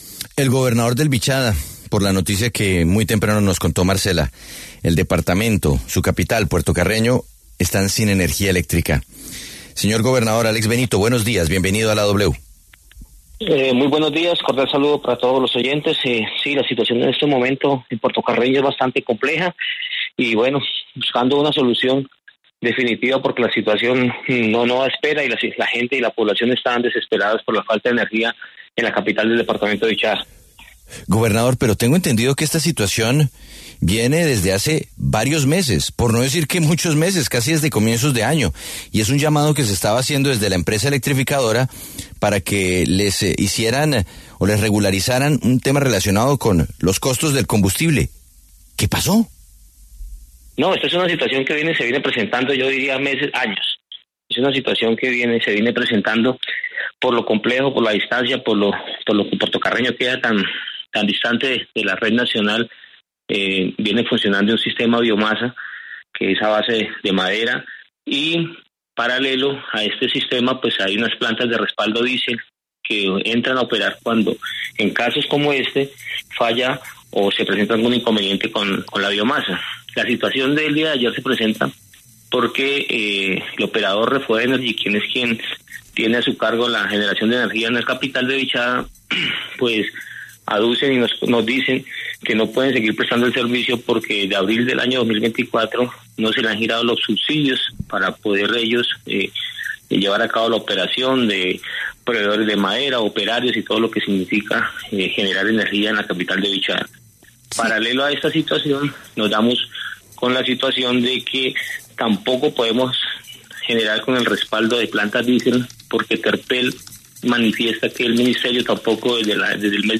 El gobernador de Vichada, Alex Benito, pasó por los micrófonos de La W, con Julio Sánchez Cristo, para hablar sobre la situación que afrontan los habitantes de Puerto Carreño con el apagón energético por las deudas del Gobierno Nacional con los proveedores encargados de garantizar el suministro eléctrico en la región.